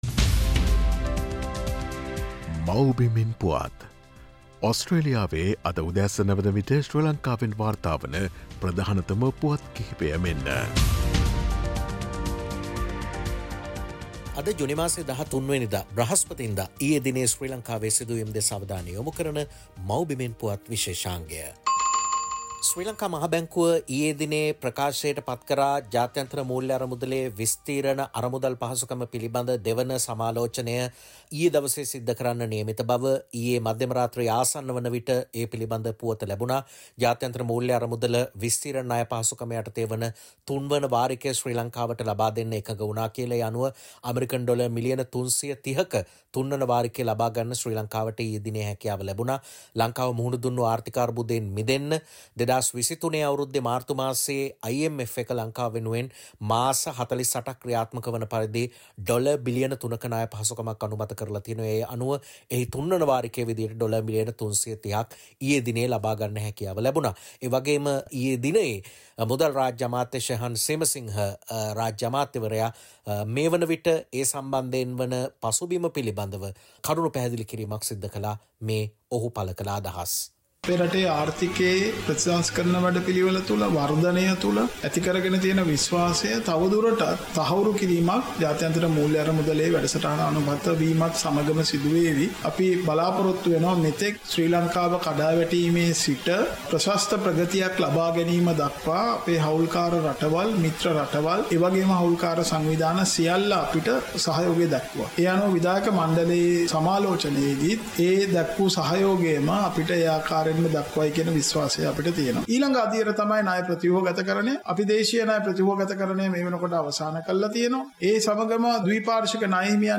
Sri Lanka Easter Sunday attack update Source: SBS / SBS Sinhala